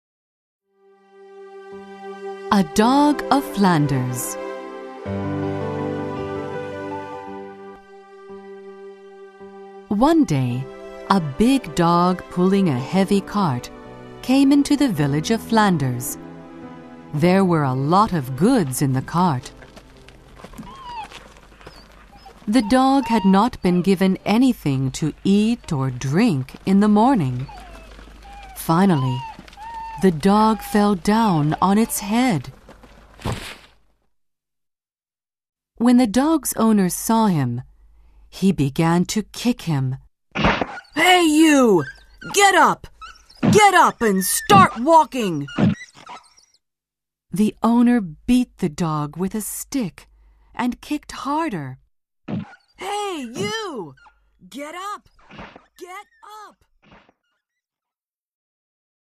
[オーディオブック] きくeigo〜フランダースの犬
本商品は、英語の朗読による音声ファイルのみの商品となっておりますが、